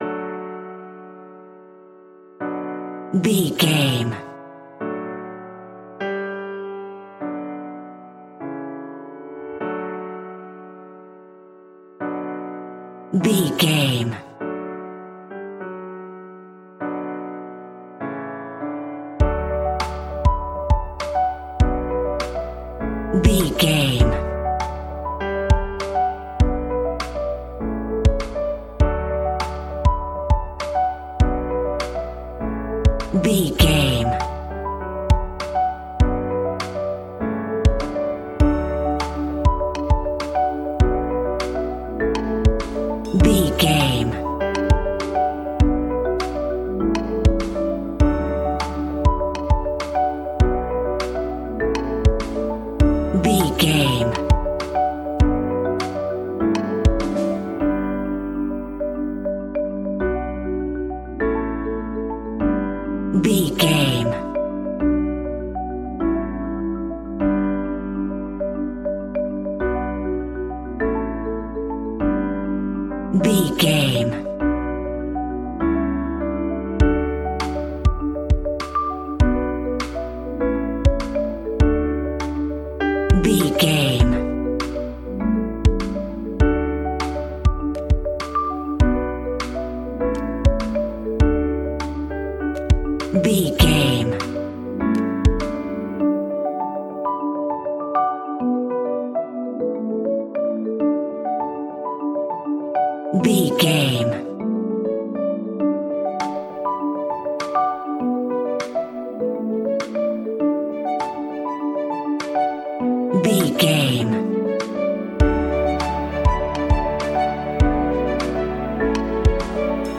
royalty free music
Ionian/Major
D
pop rock
indie pop
fun
energetic
uplifting
instrumentals
upbeat
groovy
guitars
bass
drums
organ